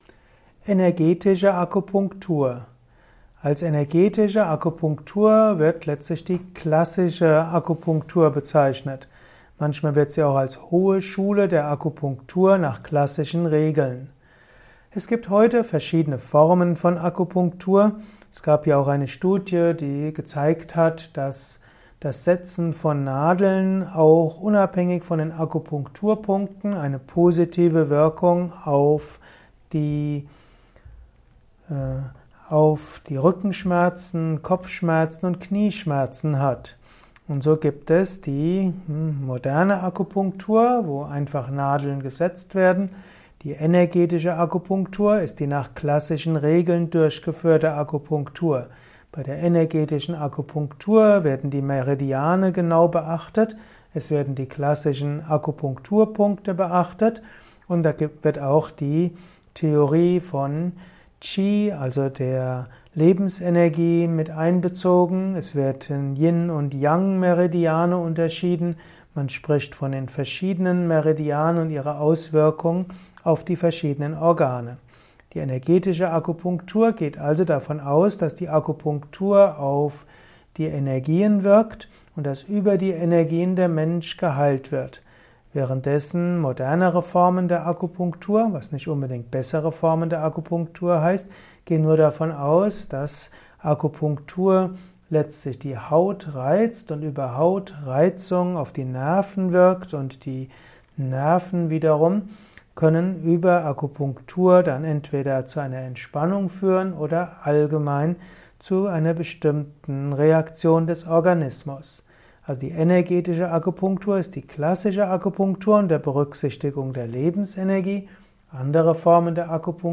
Kurzer Vortrag mit einigen interessanten Einsichten zu Energetische